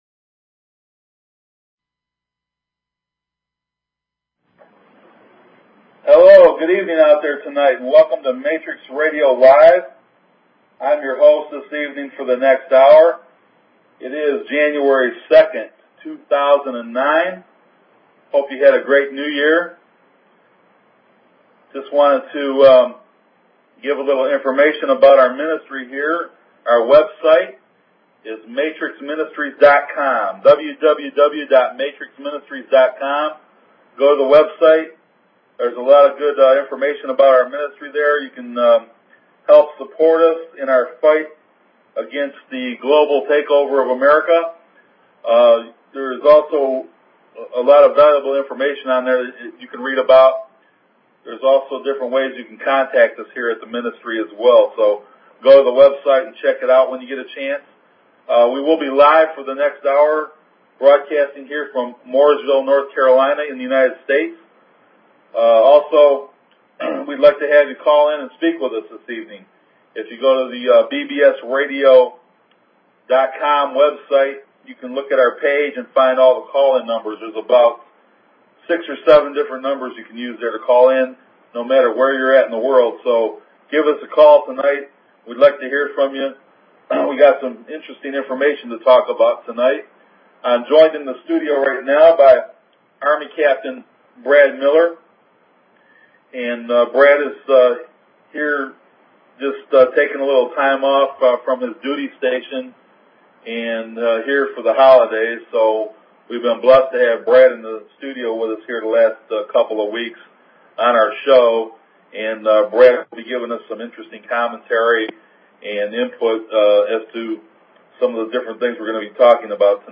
Talk Show Episode, Audio Podcast, Matrix_Radio_Live and Courtesy of BBS Radio on , show guests , about , categorized as